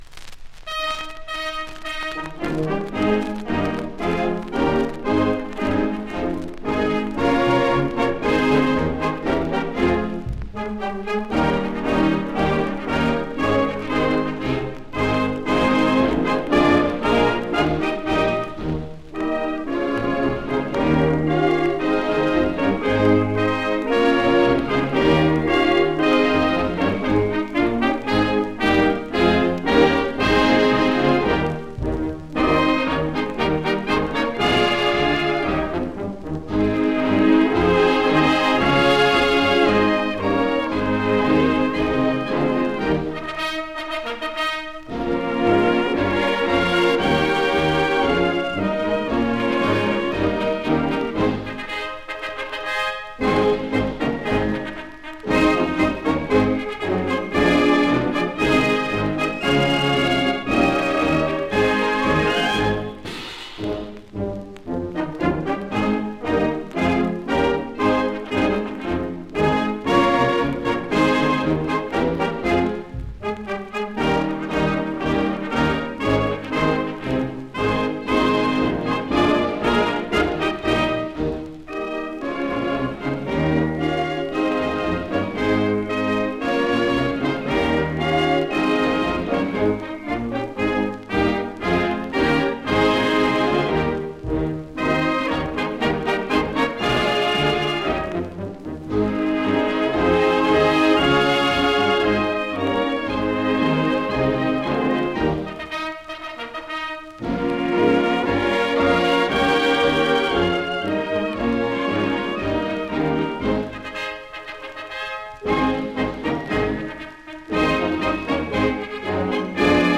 Sozialistenmars ch
Arbeidersbond voor Cultuur